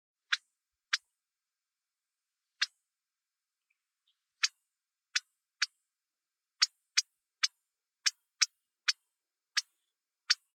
シマセンニュウ｜日本の鳥百科｜サントリーの愛鳥活動
「日本の鳥百科」シマセンニュウの紹介です（鳴き声あり）。オオヨシキリとコヨシキリの中間の大きさの小鳥。